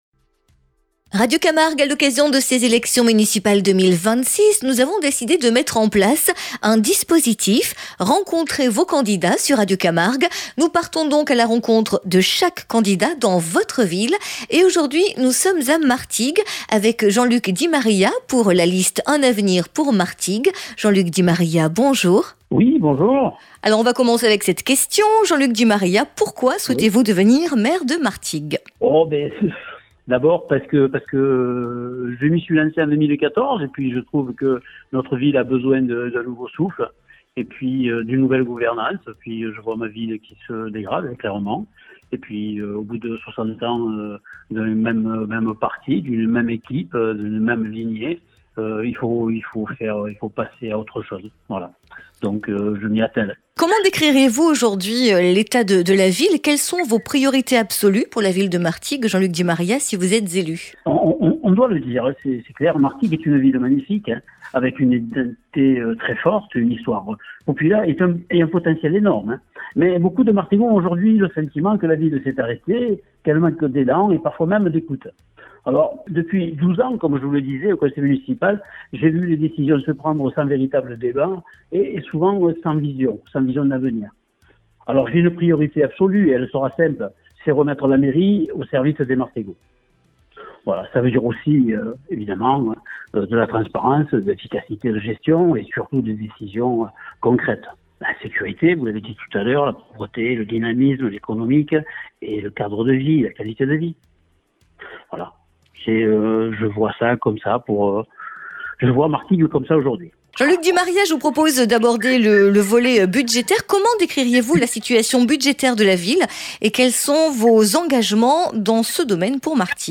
Municipales 2026 : entretien